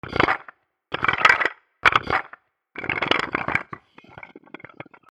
日常・釣瓶（ガラガラガラ） 01